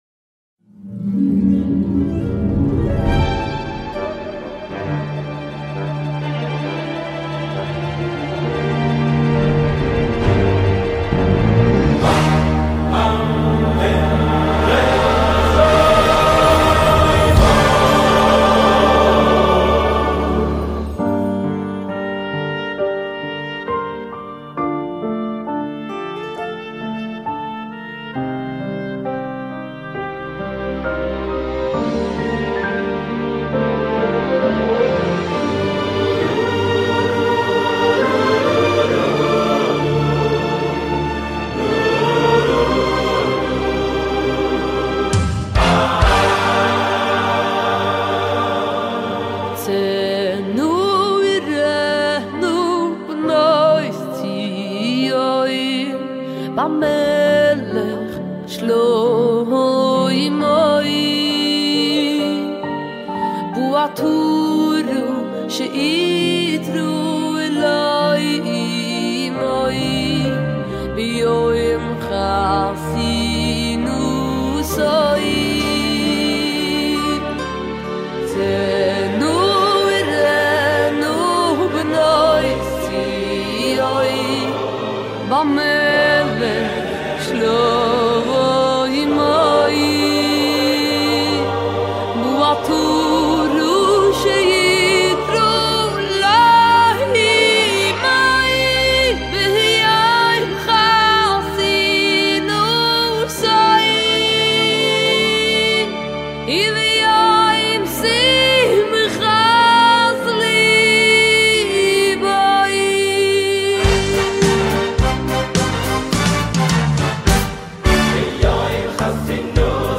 בבקשה (הוידאו באיכות נמוכה כדי שיעלה, ניתן לצפות ישירות כאן)
מוטי שטיינמץ - צאינה וראינה - ישיבה עטרת שלמה - Motty Steinmetz - Tseno Ureno_2.mp3